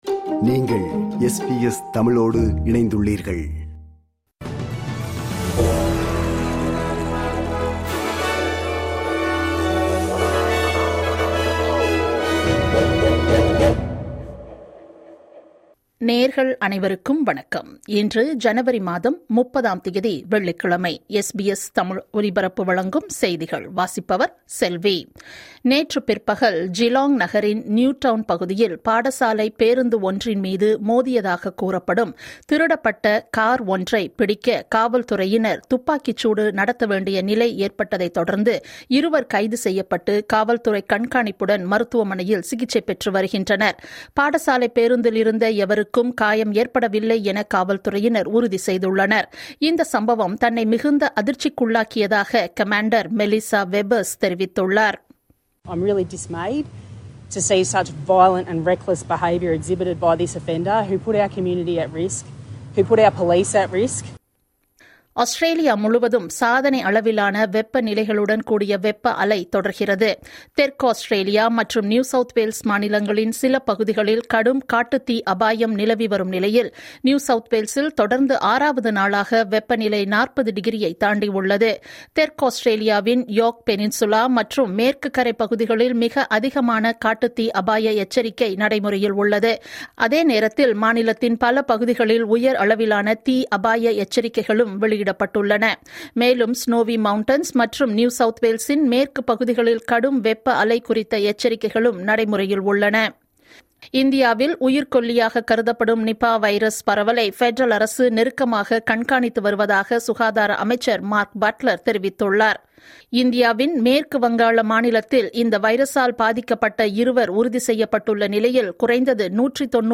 இன்றைய செய்திகள்: 30 ஜனவரி 2026 - வெள்ளிக்கிழமை
SBS தமிழ் ஒலிபரப்பின் இன்றைய (வெள்ளிக்கிழமை 30/01/2026) செய்திகள்.